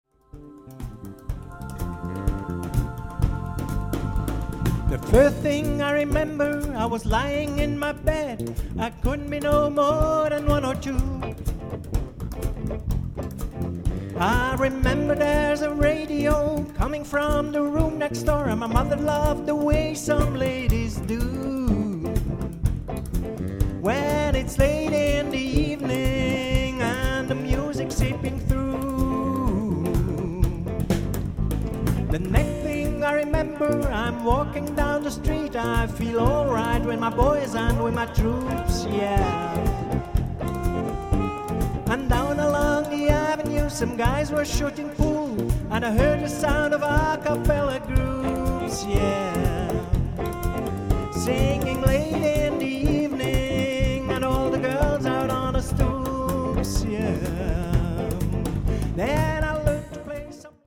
Wohlen, Sternensaal